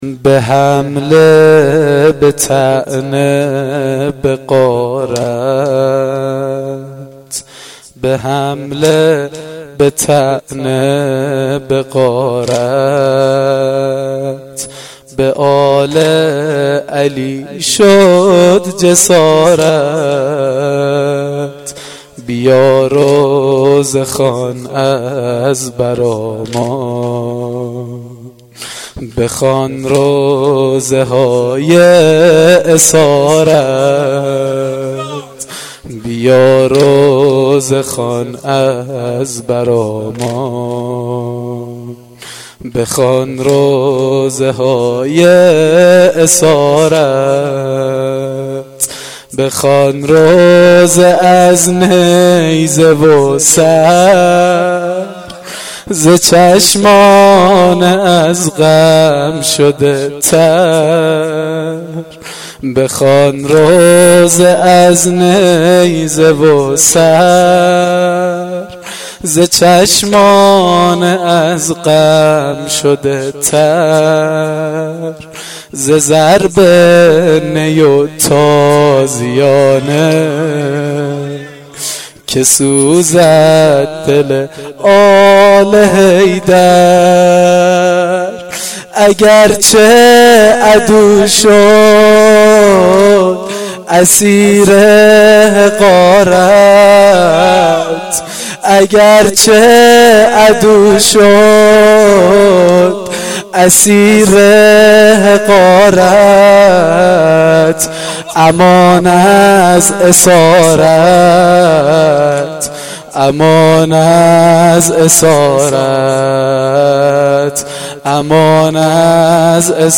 واحد سنگین شام غریبان محرم1393